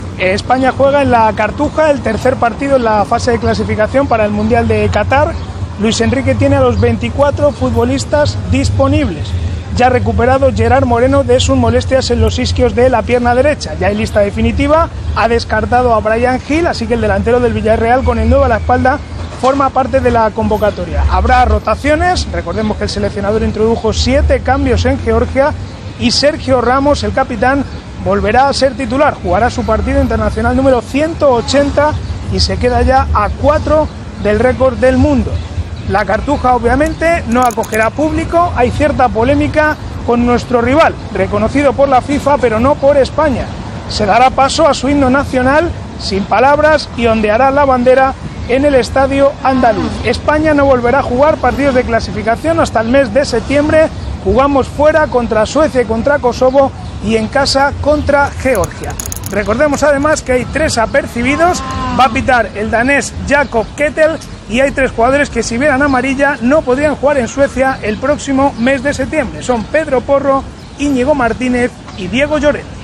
Informació, des de Sevilla, prèvia al partit de futbol masculí entre les seleccions d'España i Kosovo en la fase de classificació del Mundial de Qatar 2022
Esportiu